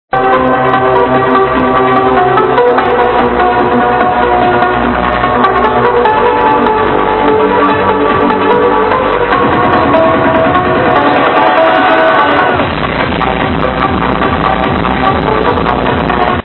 Plz ID trance tune from video (should be eazy) #2
Sorry about the quality.